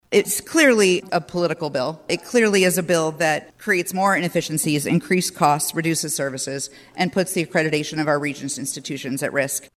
House Minority Leader Jennifer Konfrst disagrees.